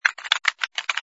sfx_keyboard_flurry04.wav